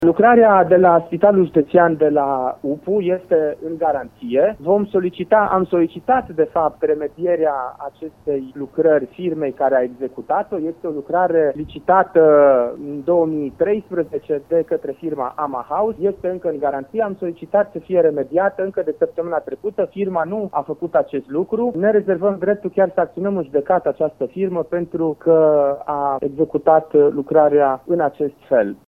Conducerea Consiliului Judeţean Arad, care a contractat şi plătit extinderea Unităţii de Primiri Urgenţe ia în calcul chiar şi acţionarea în judecată a firmei care a realizat lucrarea, spune vicepreşedintele instituţiei, Adrian Ţolea.